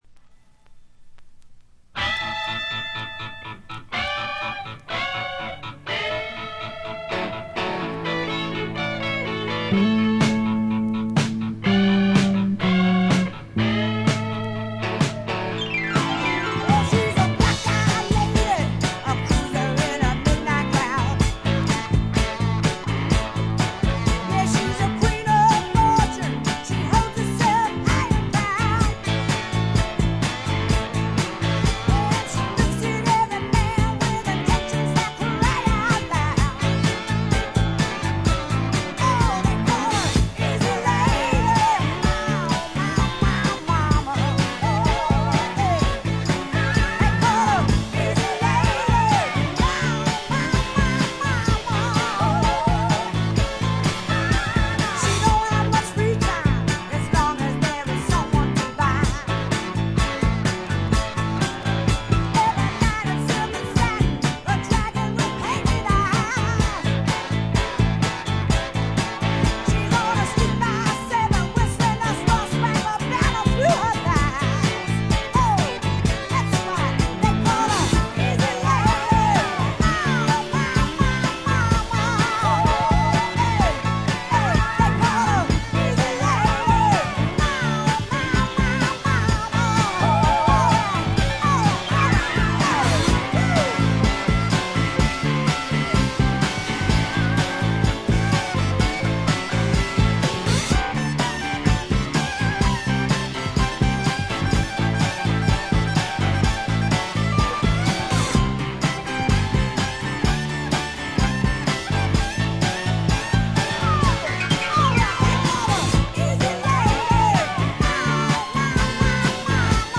部分試聴ですがほとんどノイズ感無し。
ファンキーでタイト、全編でごきげんな演奏を繰り広げます。
試聴曲は現品からの取り込み音源です。